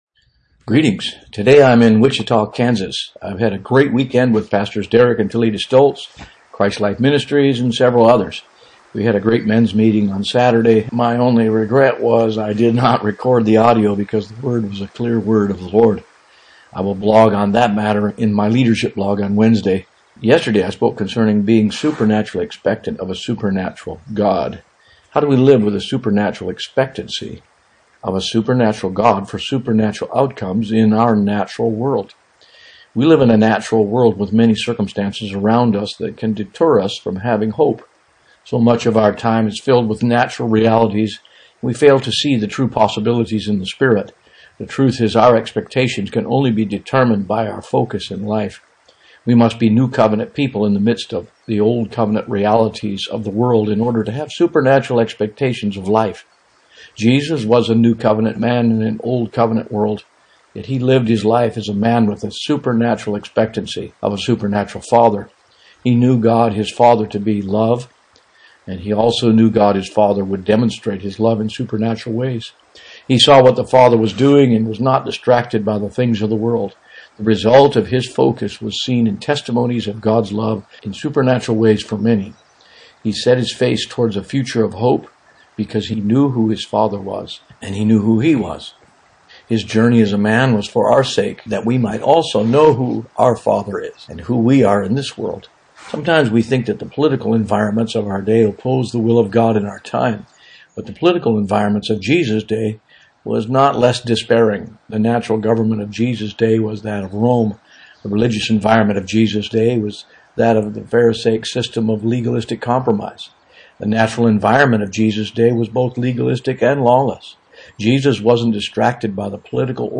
Blog In Audio: